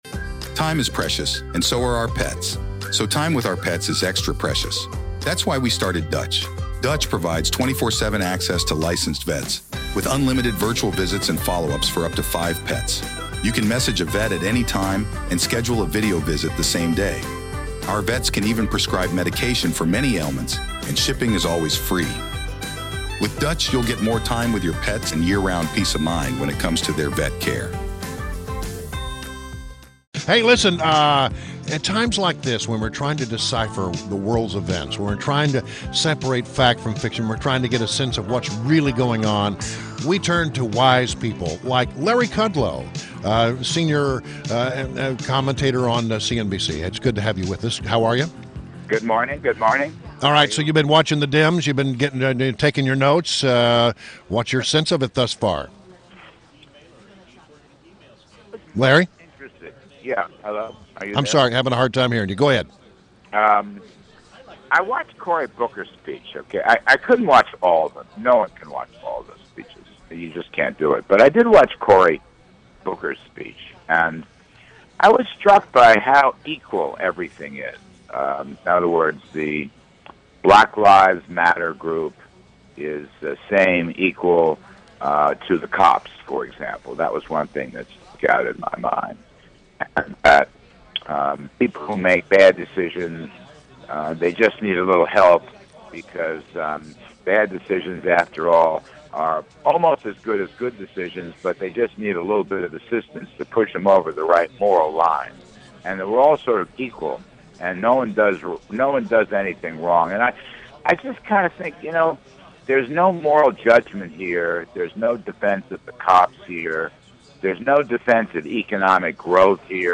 WMAL Interview - Larry Kudlow - 07.26.16